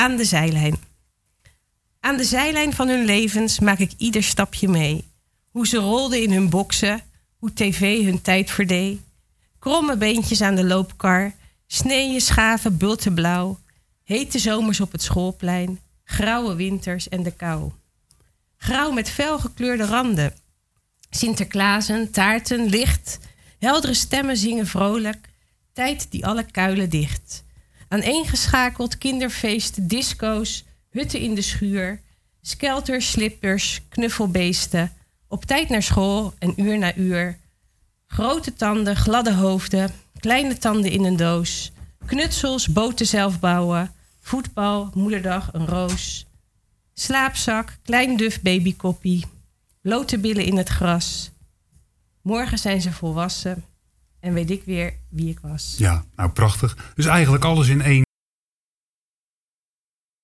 In�het�programma�Kletskoek�las�zij�vooruit�eigen�werk.